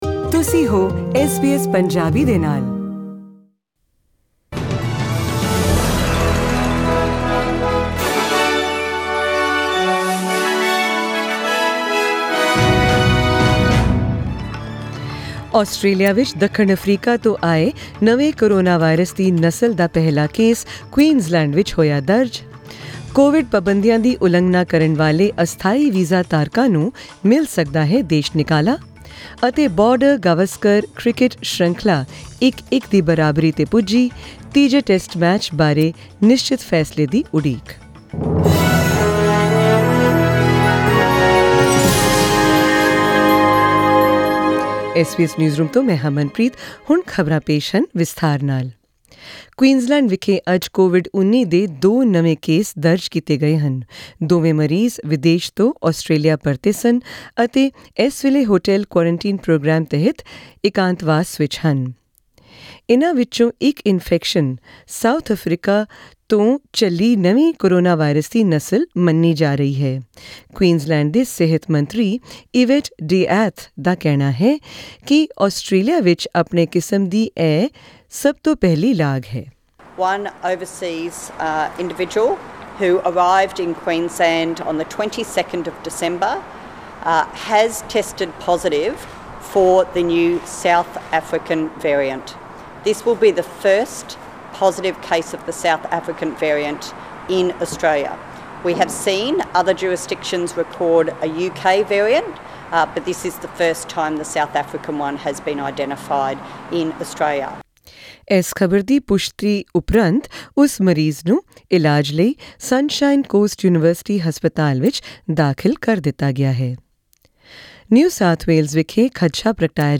In tonight's news bulletin: Australia has confirmed its first case of the South African variant of COVID-19 in Queensland; Immigration Minister Alex Hawke says temporary visa holders who breach COVID-19 safety rules could be fined or deported, and in cricket, a decision is imminent on whether Sydney can still host the next Test match of the Border-Gavaskar series, as India level the series 1-1.